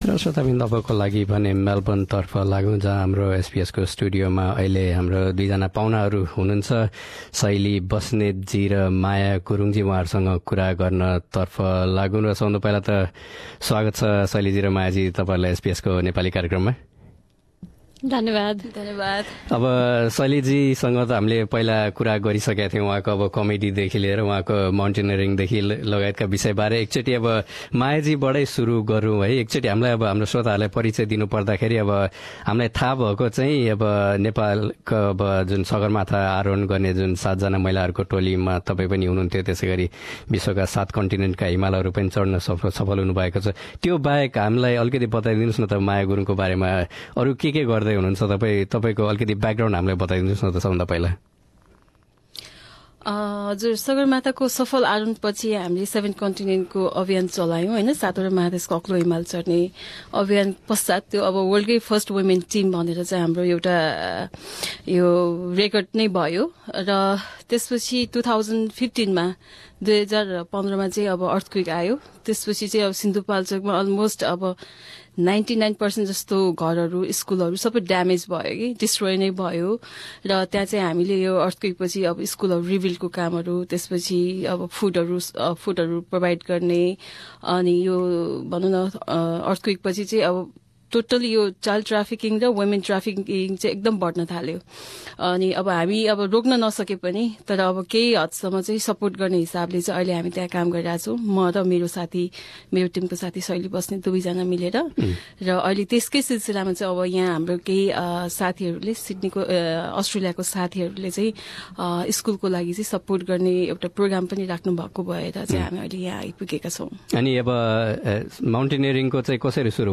एसबीएस नेपालीसँग गरेको कुराकानी।